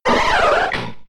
Cri de Colossinge K.O. dans Pokémon X et Y.